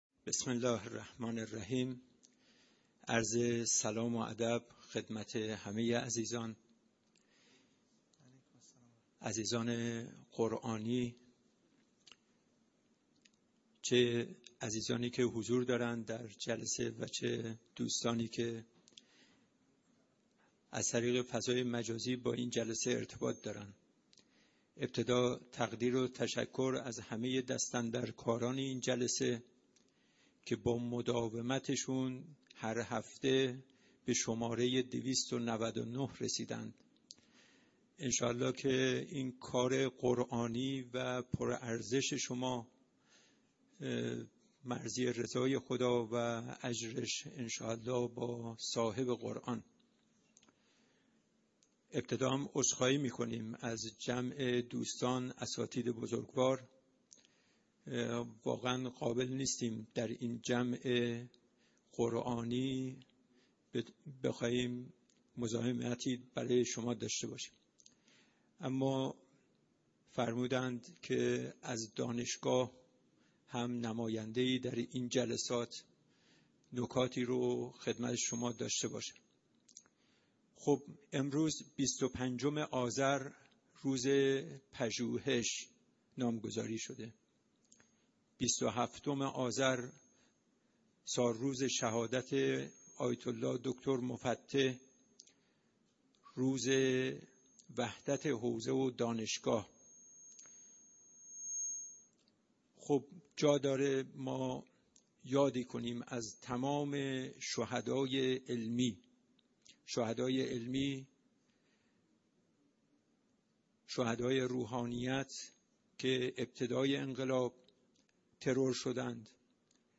گزارش صوتی دویست و نود و نهمین کرسی تلاوت و تفسیر قرآن کریم - پایگاه اطلاع رسانی ضیافت نور